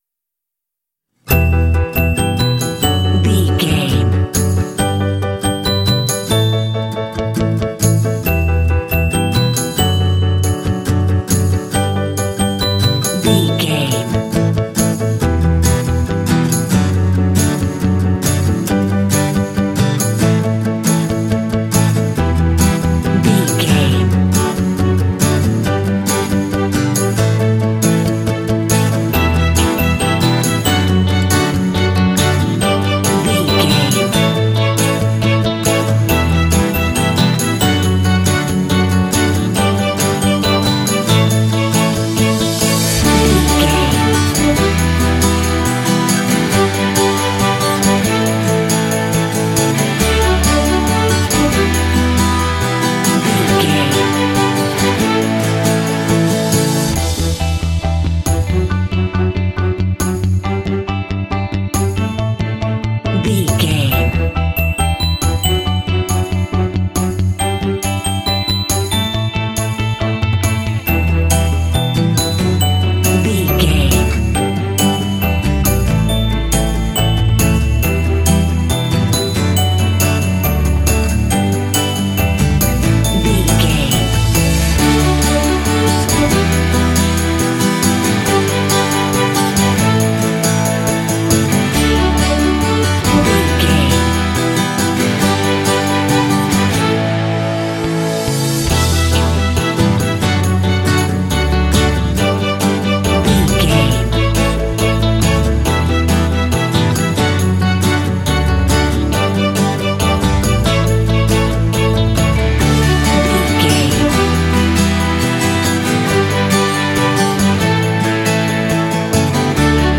Fun and cheerful indie track with bells.
Uplifting
Ionian/Major
D
optimistic
bright
piano
bass guitar
electric guitar
strings
pop
symphonic rock